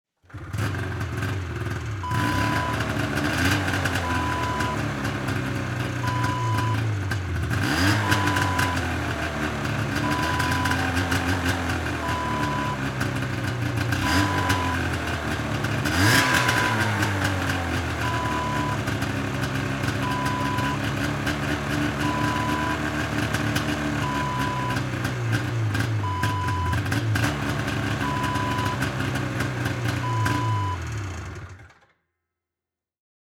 Snowmobile: start, idle & turn off sound effect .wav #1
Description: Snowmobile starts, idles and turns off
Properties: 48.000 kHz 24-bit Stereo
A beep sound is embedded in the audio preview file but it is not present in the high resolution downloadable wav file.
Keywords: snowmobile, snow mobile, skidoo, ski-doo, ski doo, winter, engine, start, idle, idling, turn, off
snowmobile-start-preview-1.mp3